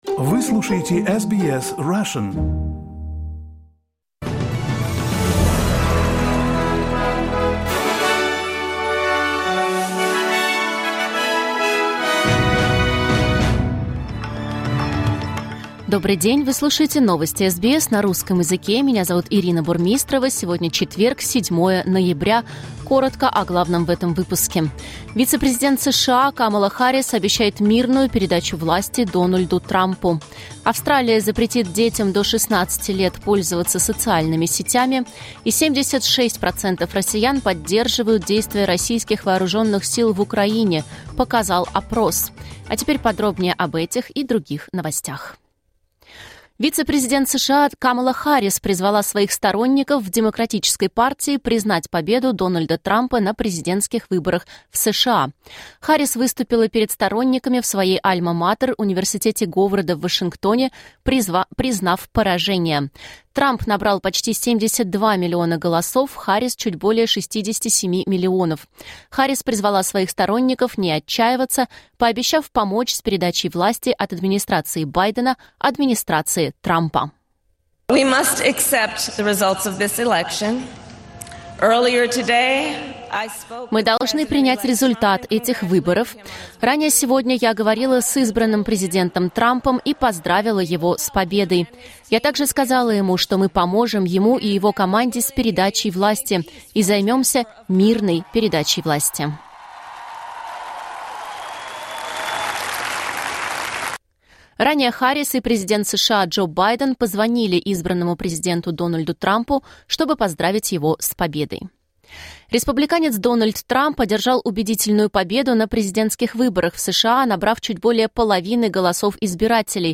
Новости SBS на русском языке — 07.11.2024